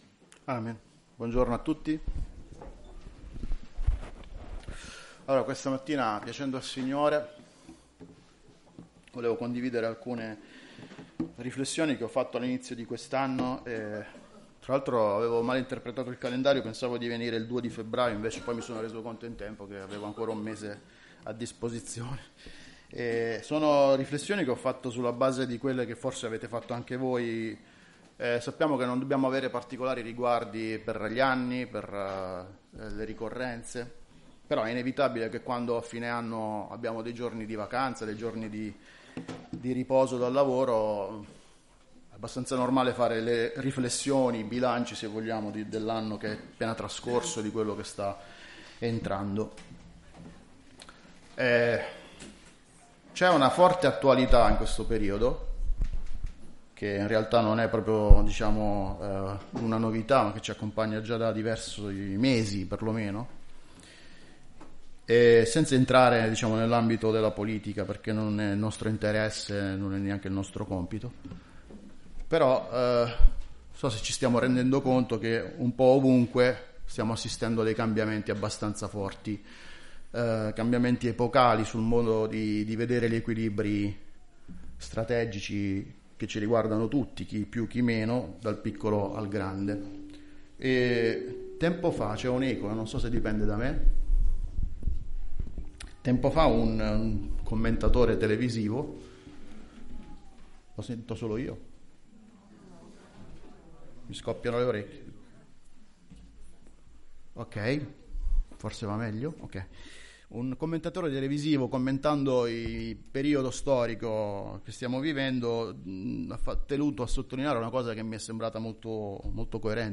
Chiesa Cristiana Evangelica - Via Di Vittorio, 14 Modena
Predicazioni